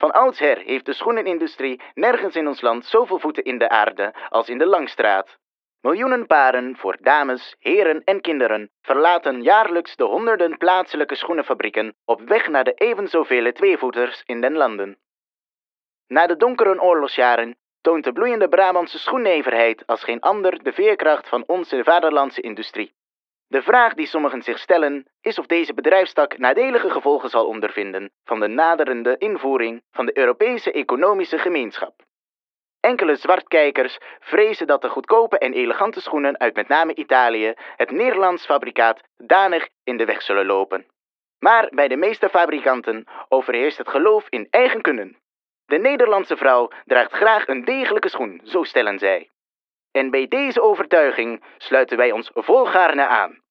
Wij spreken de stemmen in, helemaal is stijl van de historische schoenenindustrie.
De opnames worden weergegeven als een leerrooier & journaal uitzending. Hiermee nemen we bezoekers mee terug in de tijd van de oude schoenenindustrie.